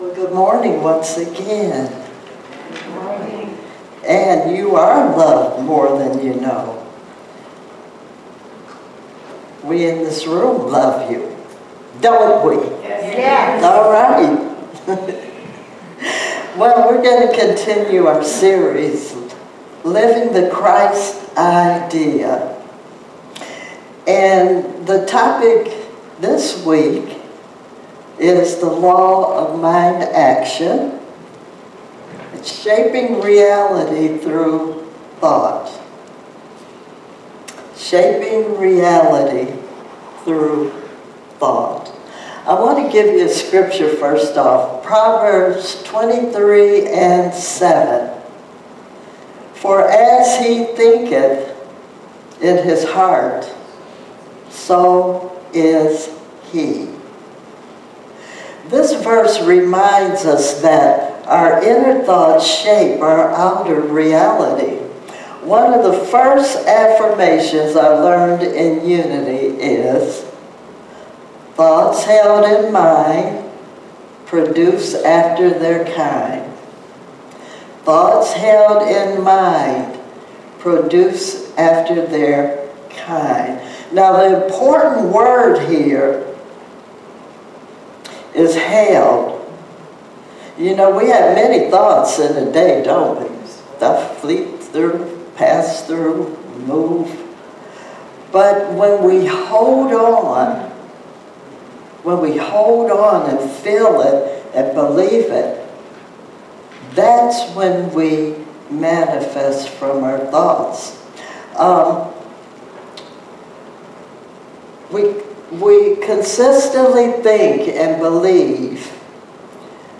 Sermons 2025